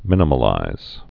(mĭnə-mə-līz)